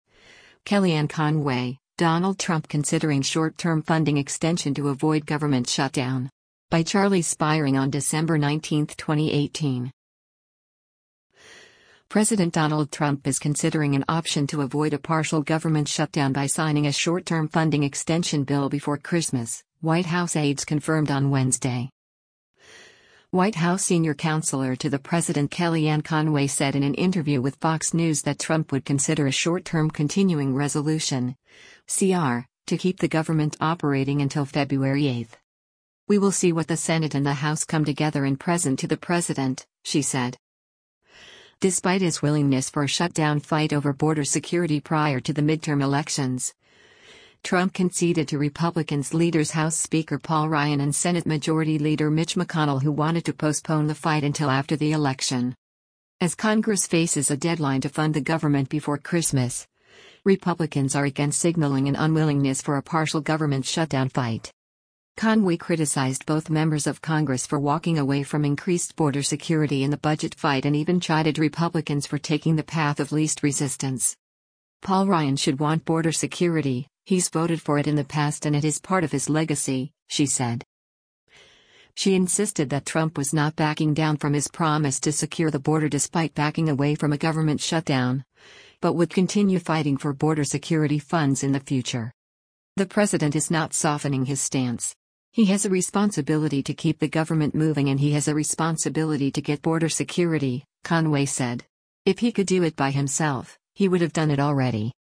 White House Senior Counselor to the President Kellyanne Conway said in an interview with Fox News that Trump would consider a short-term continuing resolution (CR) to keep the government operating until February 8th.